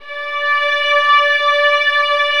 VIOLINS EN-R.wav